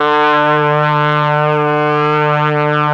RED.BRASS  8.wav